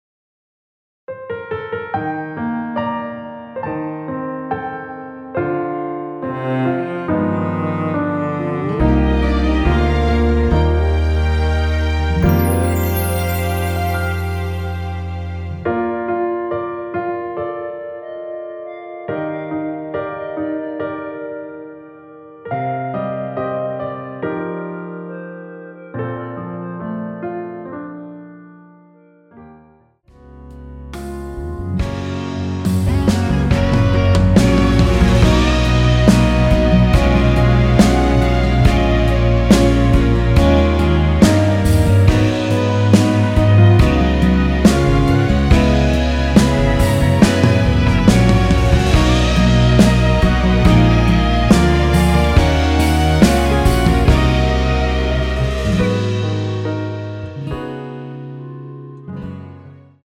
원키에서(-1)내린 멜로디 포함된 MR입니다.(미리듣기 확인)
Bb
앞부분30초, 뒷부분30초씩 편집해서 올려 드리고 있습니다.
중간에 음이 끈어지고 다시 나오는 이유는